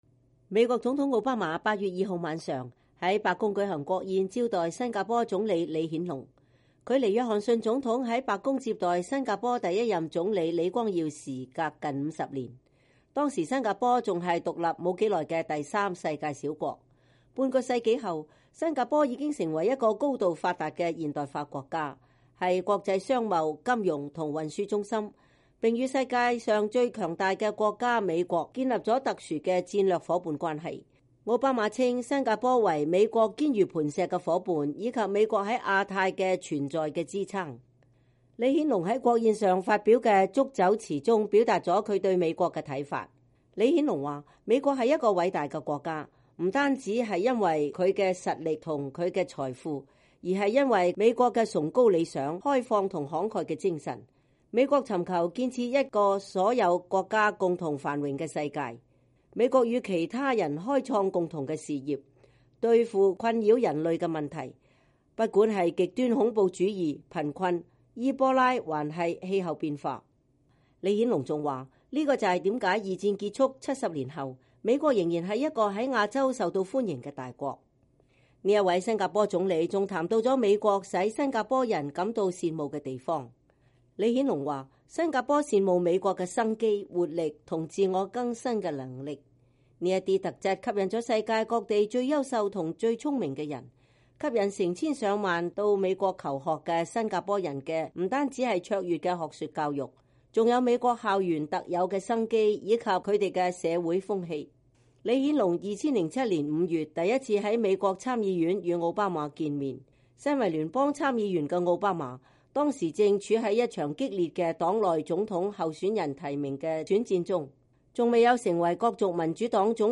我們從新加坡總理李顯龍在奧巴馬總統為他舉行的國宴上發表的祝酒辭裡可以一探究竟。李顯龍還透露了他第一次與奧巴馬見面時對他的印象。